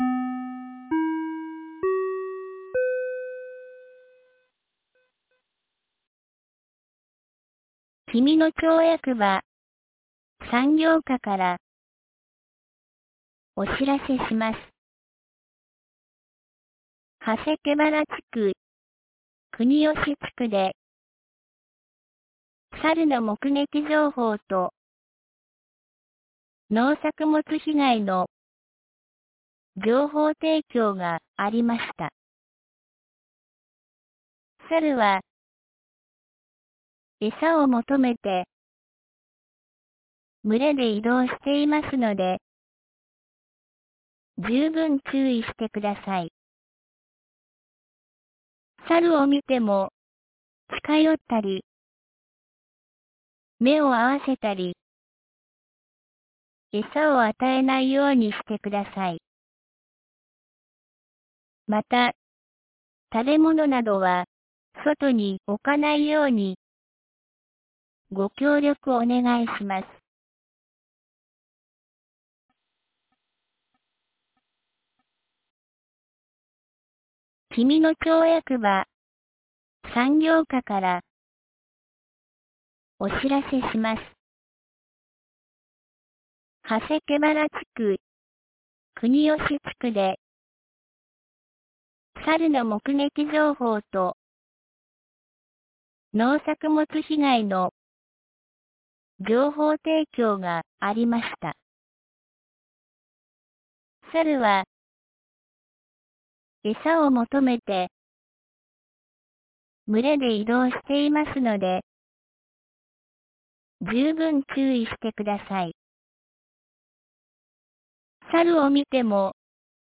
2025年07月29日 17時07分に、紀美野町より国吉地区、長谷毛原地区、上神野地区へ放送がありました。